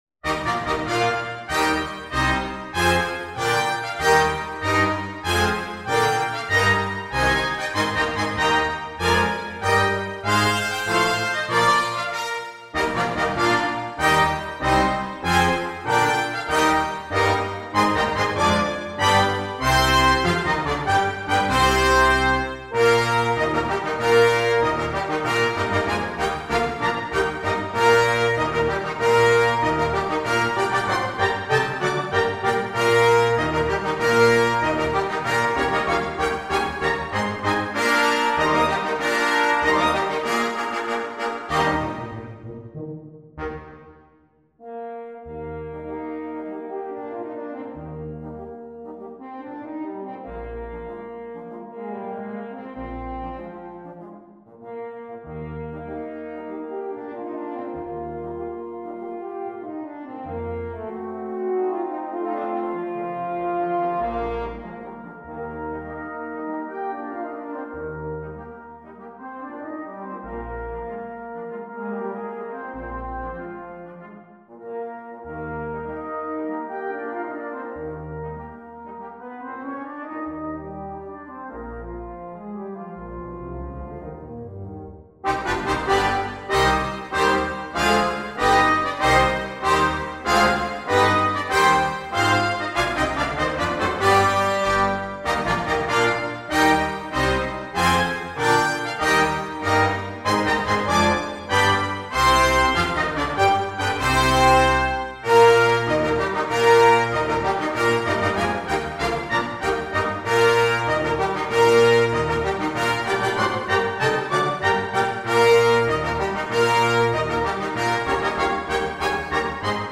2. Brass Ensemble
10 brass players
without solo instrument
Classical
Part 4: Flugelhorn
Part 10: Tuba – Bass clef